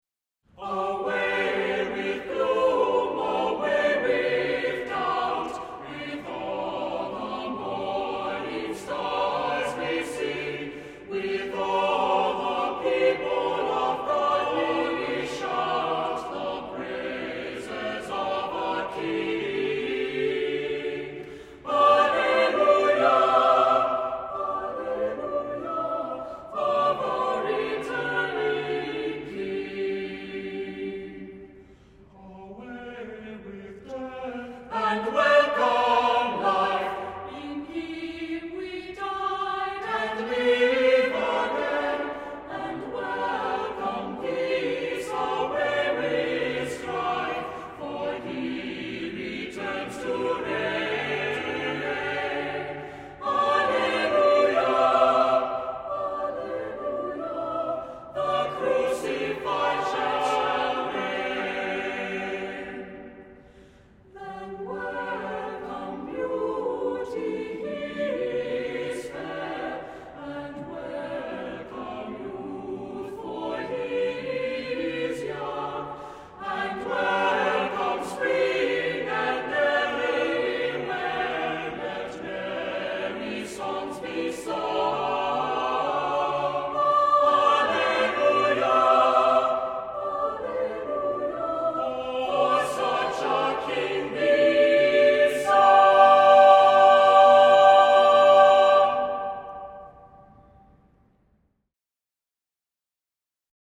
Music Category:      Christian